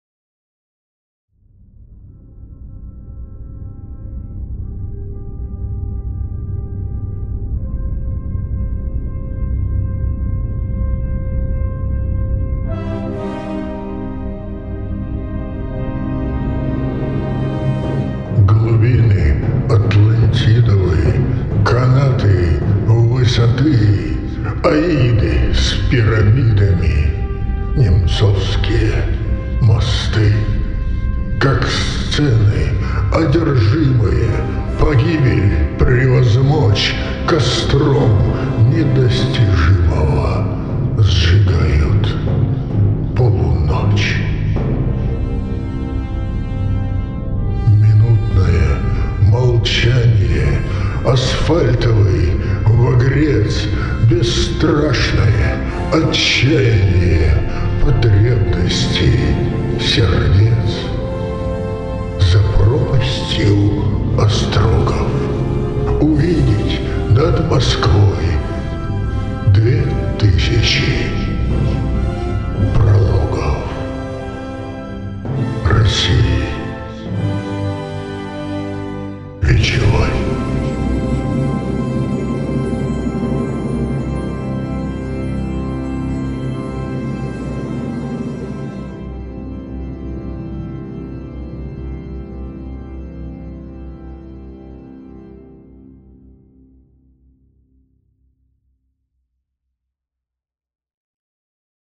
Спектакль на Немцовом Мосту.